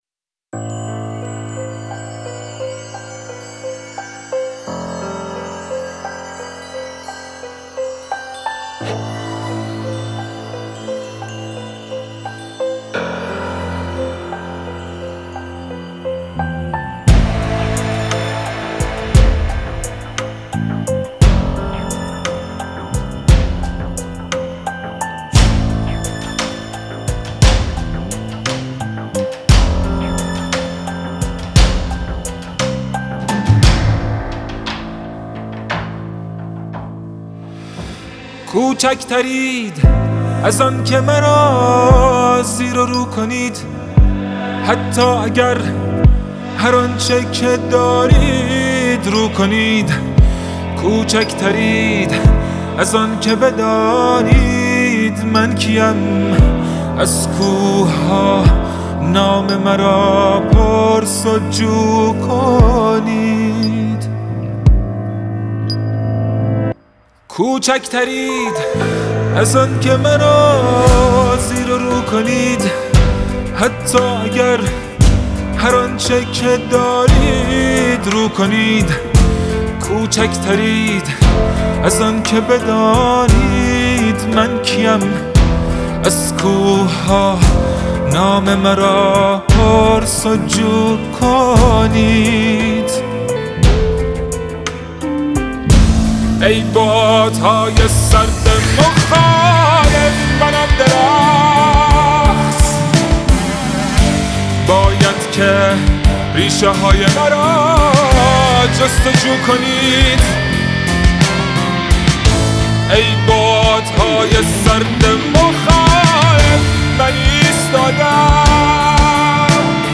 قطعه‌ای با تم حماسی